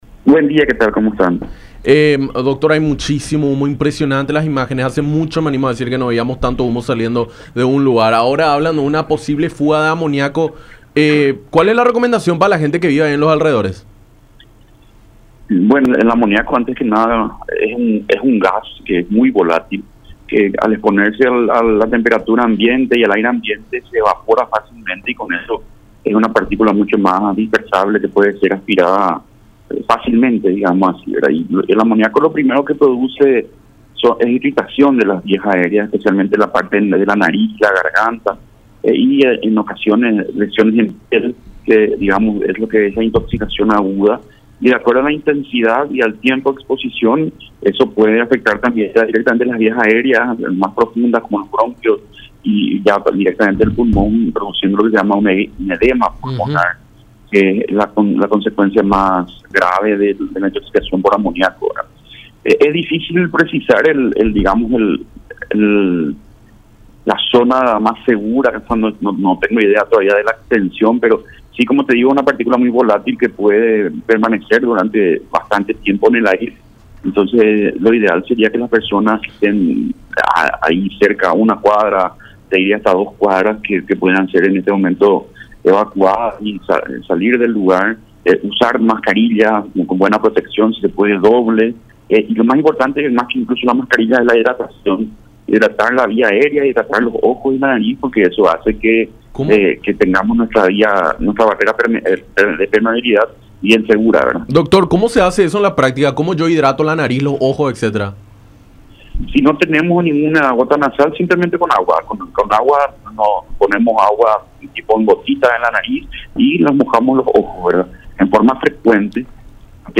en diálogo con Enfoque 800 por La Unión